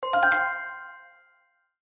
На этой странице собраны энергичные звуки победы и выигрыша — от фанфар до коротких мелодичных оповещений.
Звук радостной победы в игре